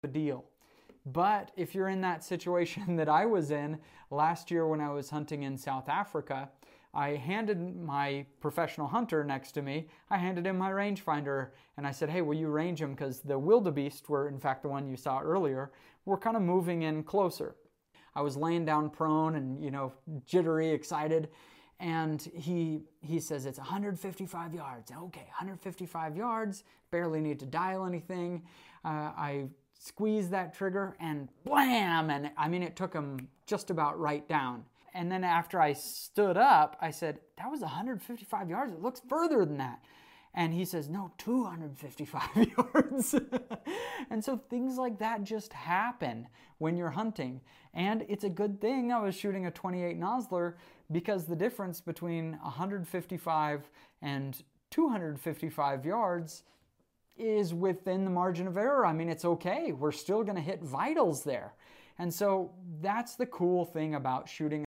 7mm 08 vs 7mm Rem Mag sound effects free download